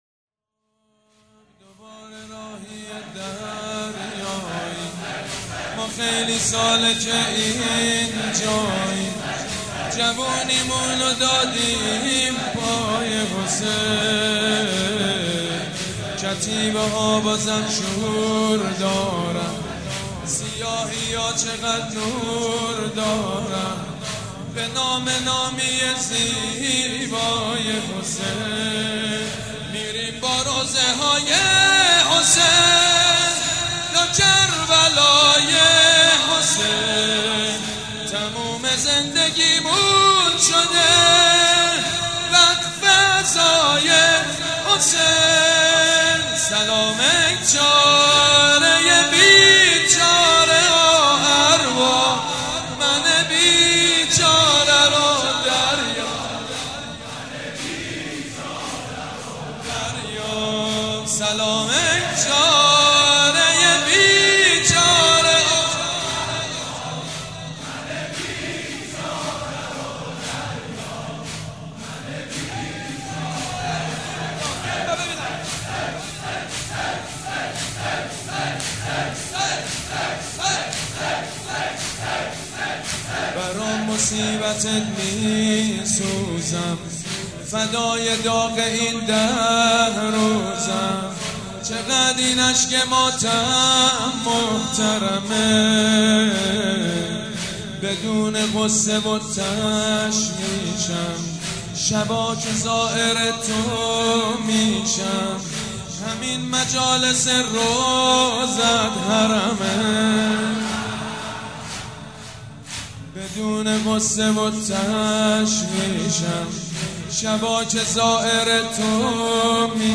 شب اول محرم - به نام نامی حضرت مسلم(ع)
سید مجید بنی فاطمه
زمینه سید مجید بنی فاطمه